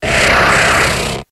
Grito de Kadabra.ogg
Grito_de_Kadabra.ogg.mp3